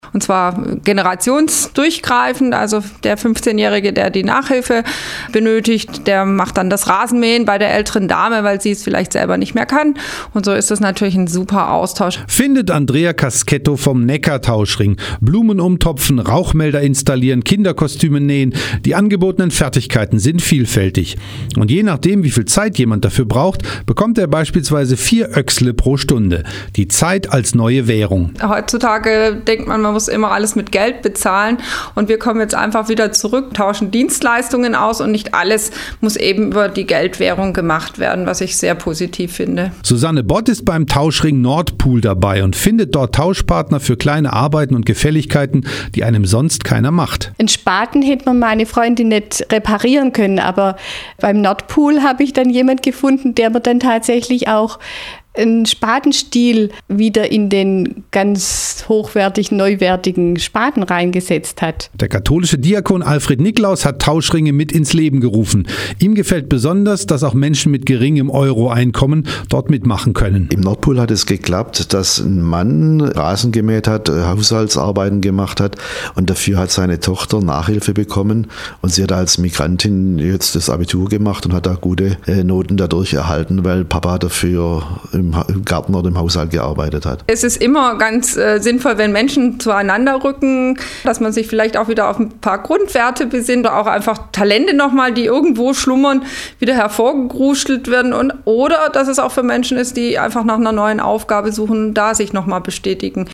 am vergangenen Sonntag lief ein Beitrag bei den Privatsendern bei welchem u.a. der NeckarTauschRing vorgestellt wurde